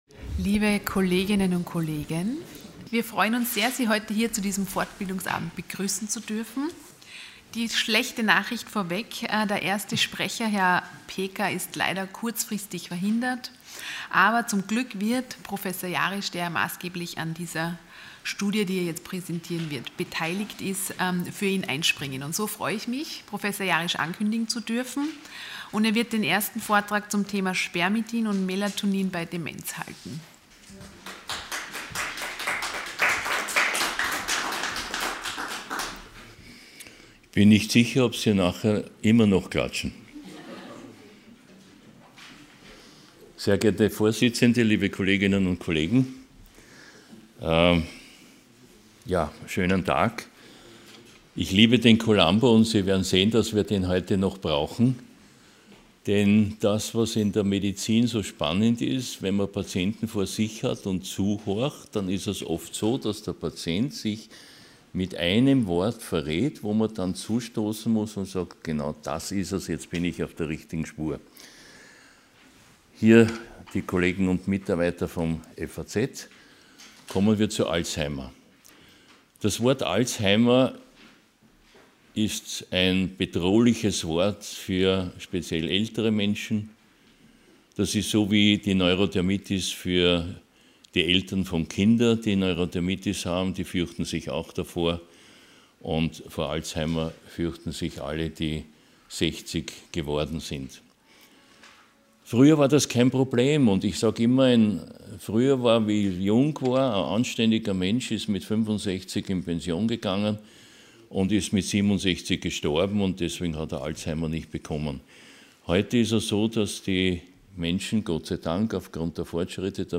Sie haben den Vortrag noch nicht angesehen oder den Test negativ beendet.
Ort: Fach: Allgemeinmedizin Art: Fortbildungsveranstaltung Thema: - Veranstaltung: Hybridveranstaltung | Spermidin und Melatonin bei Demenz, Long COVID und COPD Moderation: